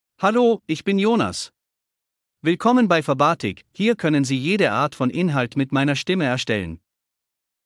Jonas — Male German (Austria) AI Voice | TTS, Voice Cloning & Video | Verbatik AI
Jonas is a male AI voice for German (Austria).
Voice sample
Male
Jonas delivers clear pronunciation with authentic Austria German intonation, making your content sound professionally produced.